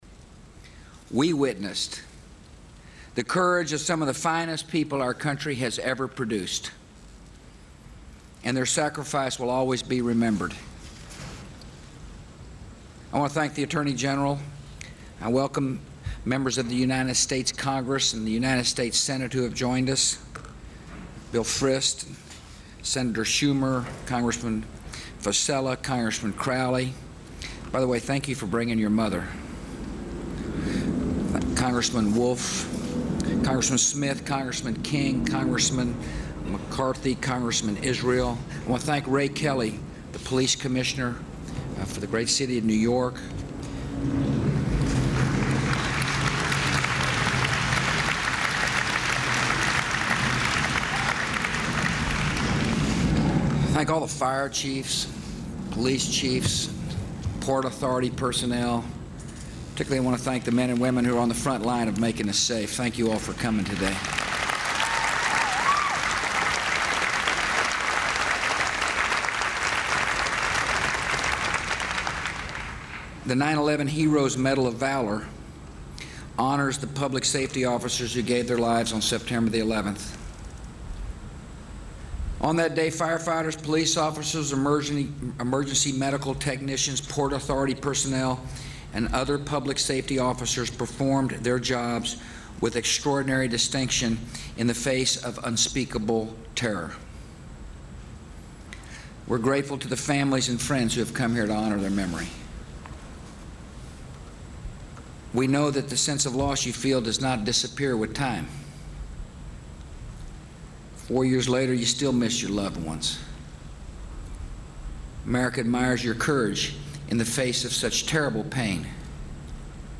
U.S. President George W. Bush speaks at the 9/11 Heroes Medal of Valor Award Ceremony
Material Type Sound recordings Language English Extent 00:11:00 Venue Note Broadcast on C-SPAN, Sept. 9, 2005.